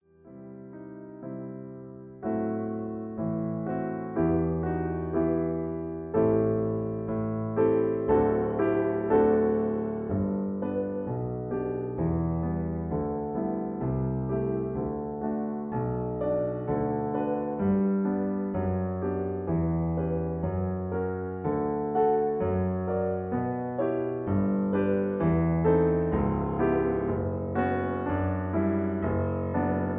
MP3 piano accompaniment track
in E (original key)